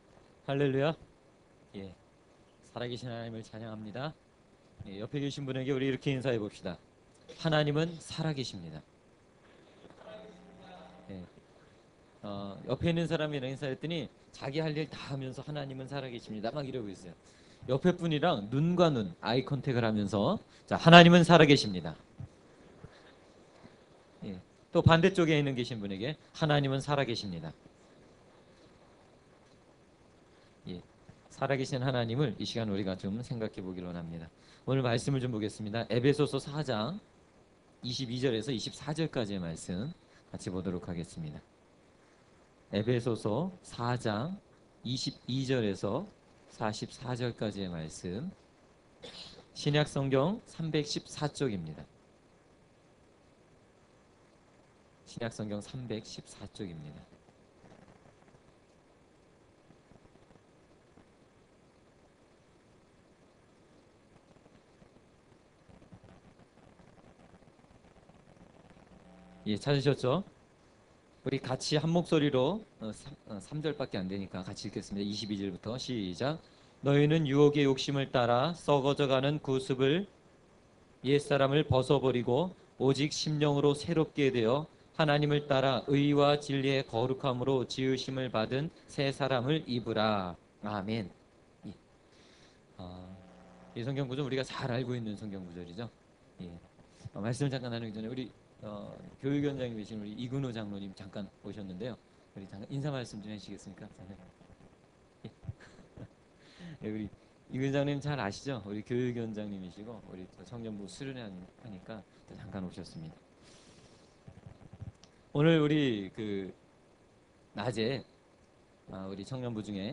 청년부 동계 수련회 2월 10일 저녁 집회